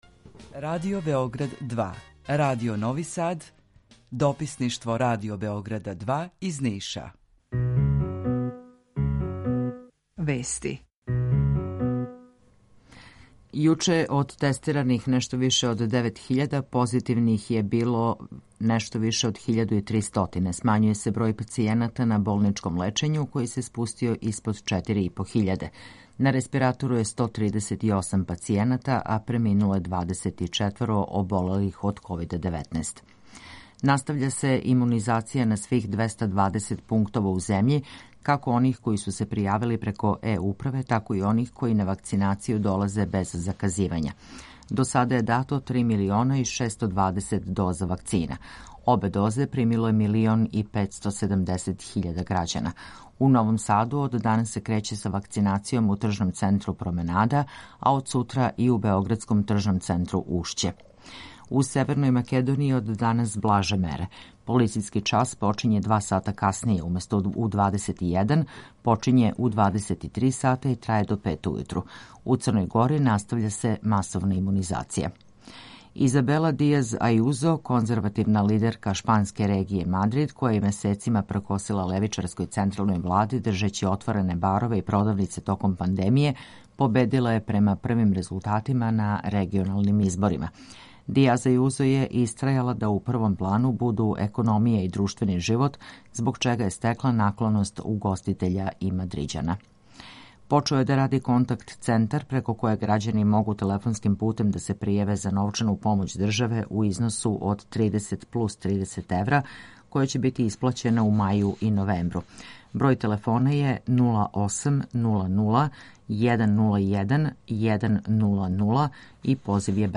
Jутарњи програм заједнички реализују Радио Београд 2, Радио Нови Сад и дописништво Радио Београда из Ниша. Cлушаоци могу да чују најновије информације из сва три града, најаве културних догађаја, теме које су занимљиве нашим суграђанима без обзира у ком граду живе.
У два сата, ту је и добра музика, другачија у односу на остале радио-станице.